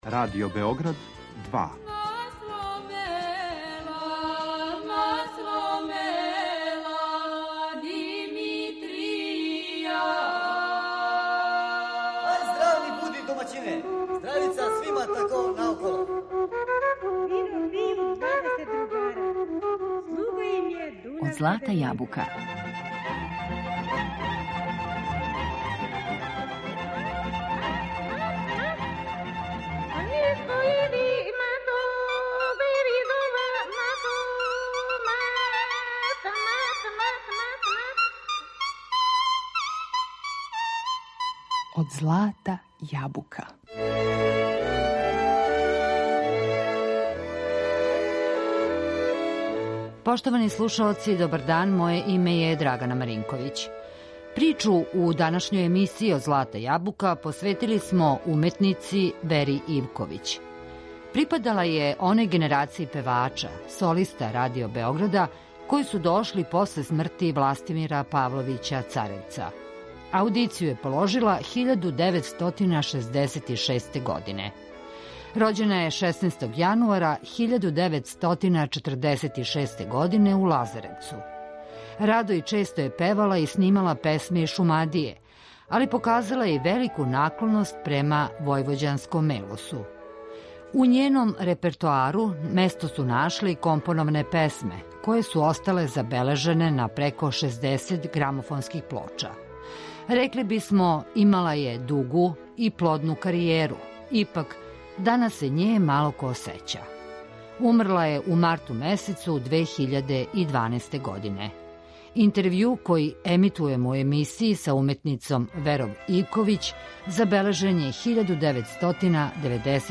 Припадала је оној генерацији певача, солиста Радио Београда, који су дошли после смрти Властимира Павловића Царевца.
Радо и често је снимала песме из Шумадије, али је показала и велику наклоност према војвођанском мелосу. У њеном репертоару место су нашле и компоноване песме, које су остале забележене на преко 60 грамофонских плоча.